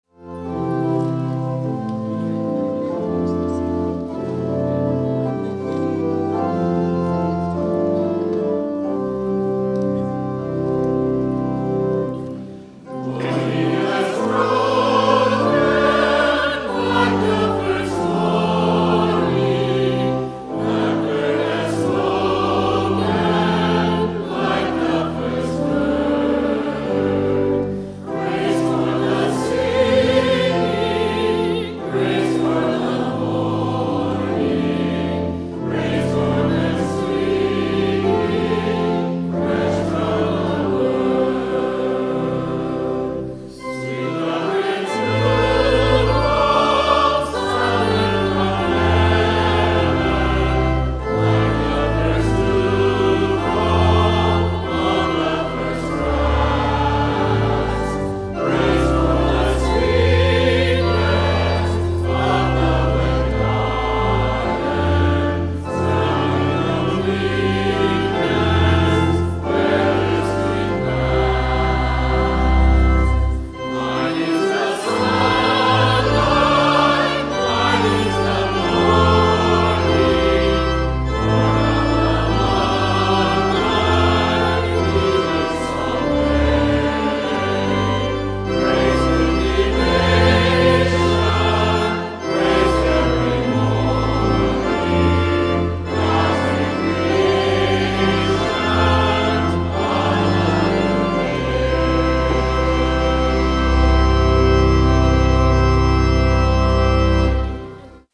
Children’s Sabbath 2018 – 9:30